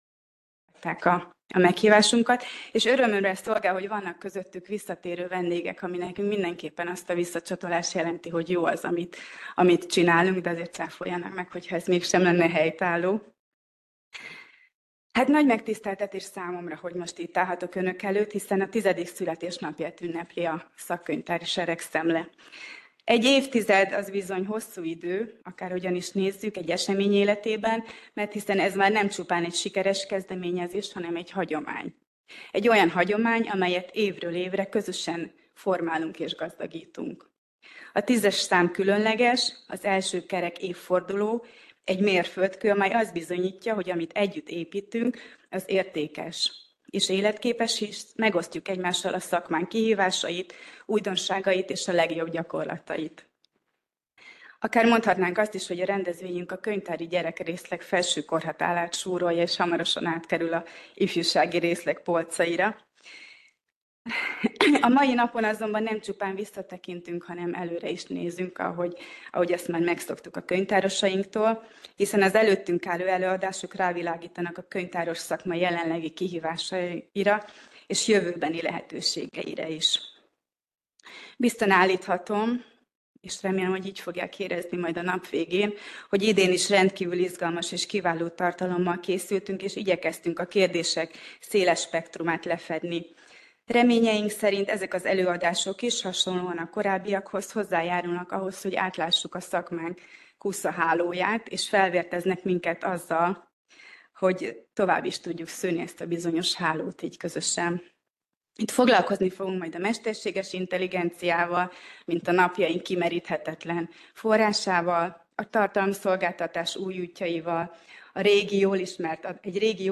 Köszöntők | VIDEOTORIUM
Elhangzott a Központi Statisztikai Hivatal Könyvtár és a Magyar Könyvtárosok Egyesülete Társadalomtudományi Szekciója Szakkönyvtári seregszemle 2025 című